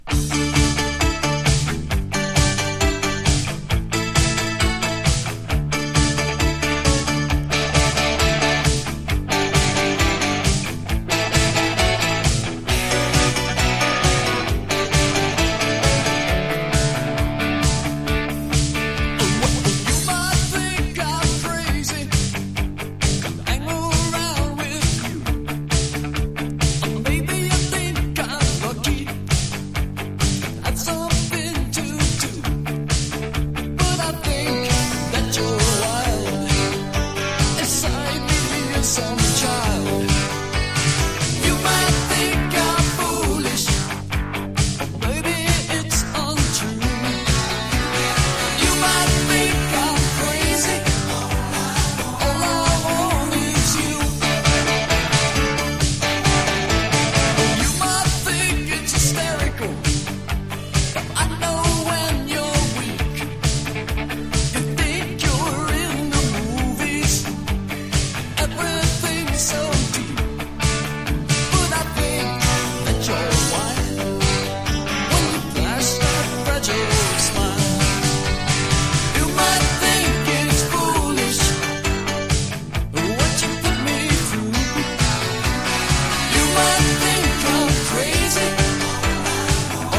1. NEW WAVE >